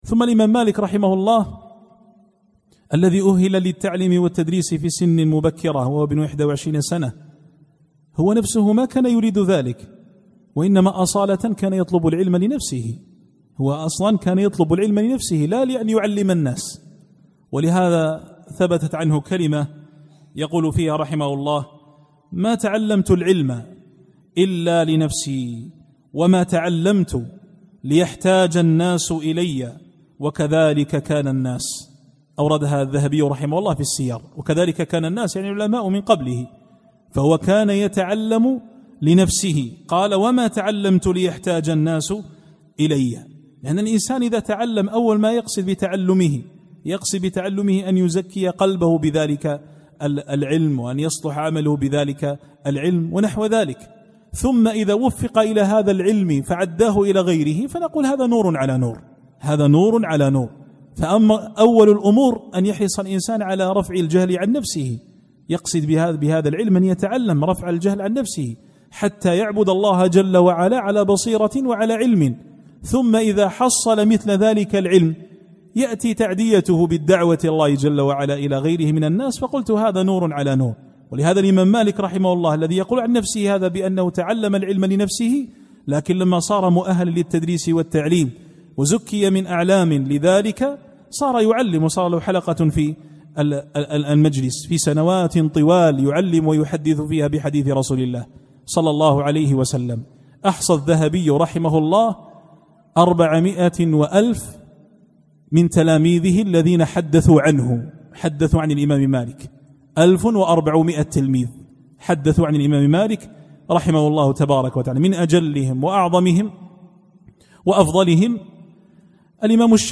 الإستماع - التحميل  الدرس الثاني